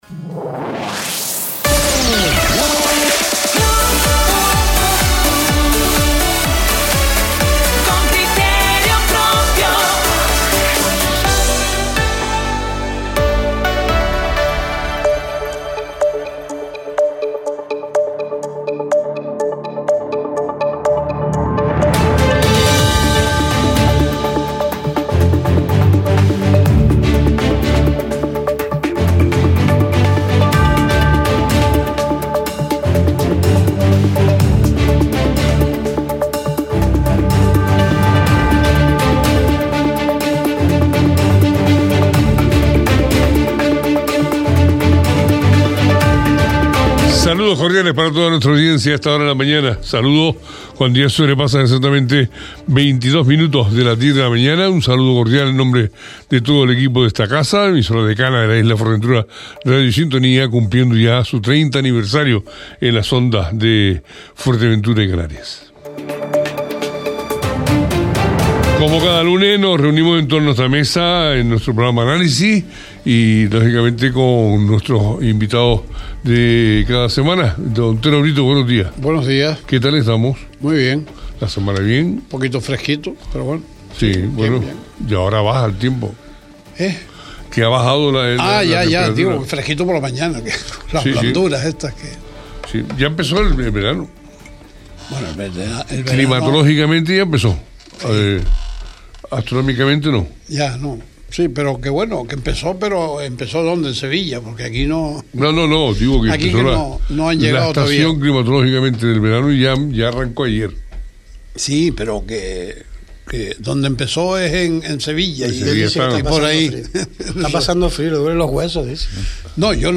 Análisis, con Claudio Gutiérrez, consejero y portavoz del Partido Popular en el Cabildo de Fuerteventura – 02.06.25 Deja un comentario